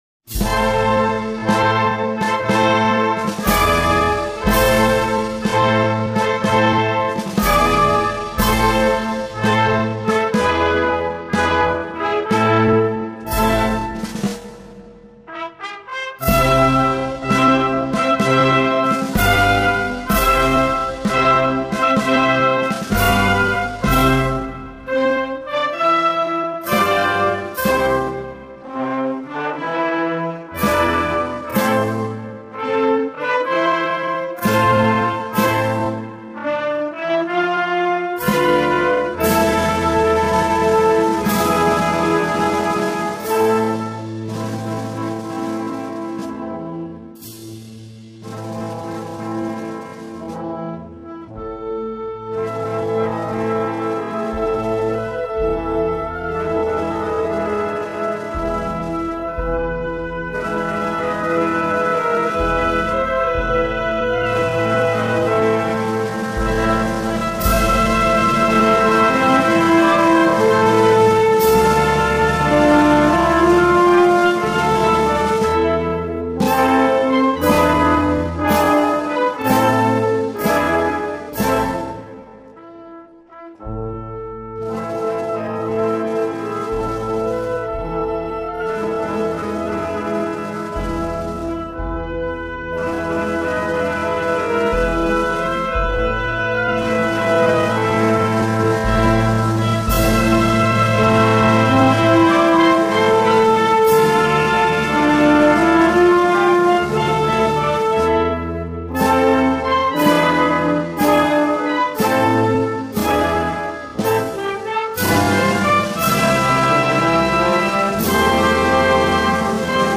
La Marcha Fúnebre a la Muerte del General O’Donnell, hoy llamada popularmente La Lagrima en Medina de Rioseco, es como en su propio título reza, una marcha fúnebre.
Esta obra se interpreta año tras año a la salida y recogida de los llamados Pasos Grandes (“La Crucifixión” y “El Descendimiento”) con isocrónica majestad en un espacio y tiempo determinados: Medina de Rioseco- Corro de Santa María- Viernes Santo por la tarde.